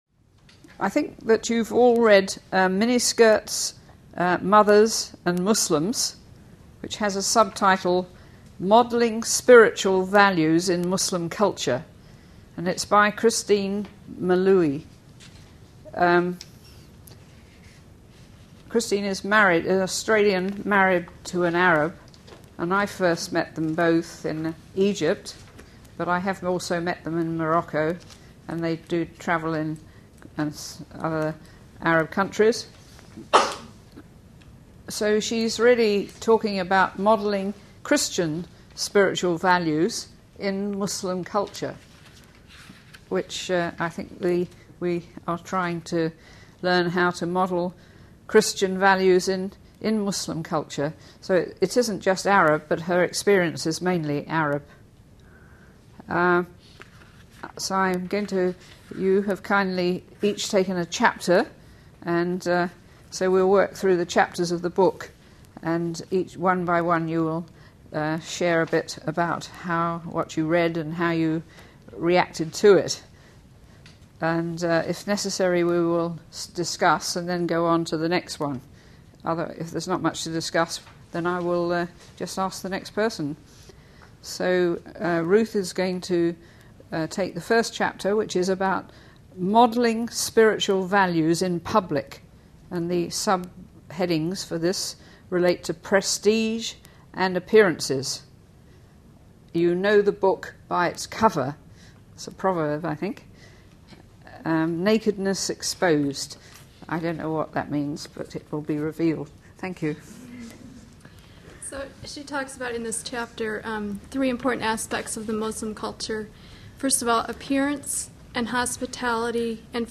These lectures were given at Columbia International University in partnership with the Zwemer Center for Muslim Studies.